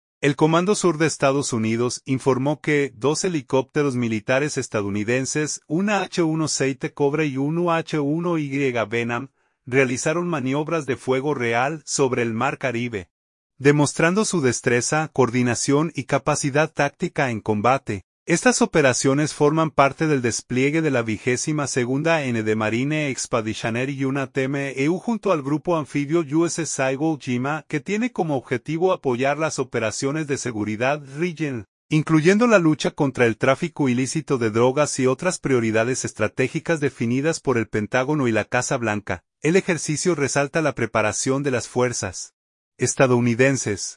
El Comando Sur de Estados Unidos informó que dos helicópteros militares estadounidenses, un AH-1Z Cobra y un UH-1Y Venom, realizaron maniobras de fuego real sobre el Mar Caribe, demostrando su destreza, coordinación y capacidad táctica en combate.